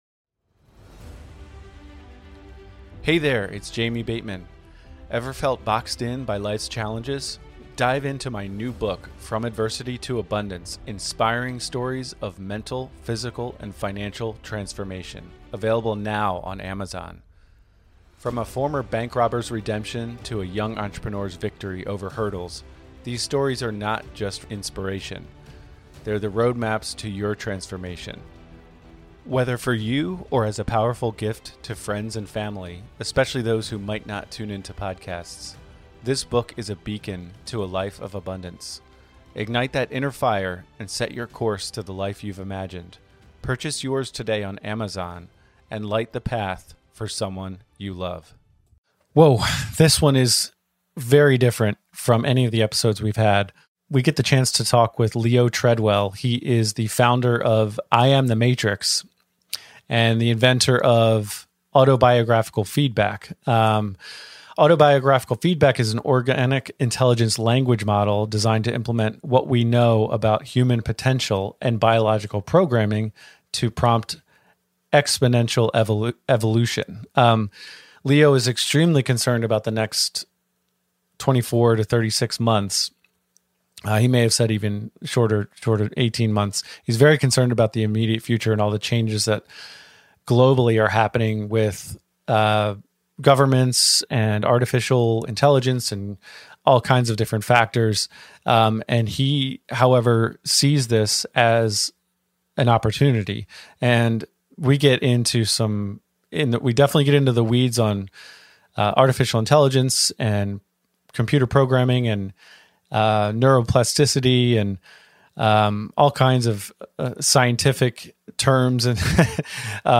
Our show brings you riveting, interview-based stories from seasoned professionals and inspiring newcomers alike, each sharing their unique journey from struggle to success in the competitive arena of real estate.